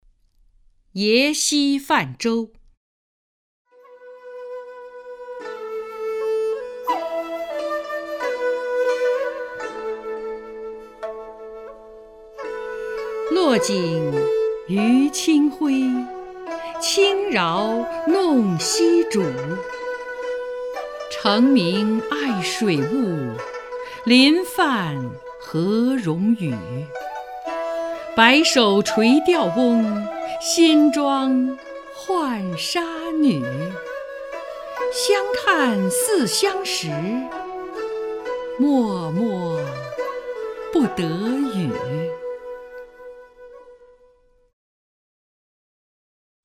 张筠英朗诵：《耶溪泛舟》(（唐）孟浩然)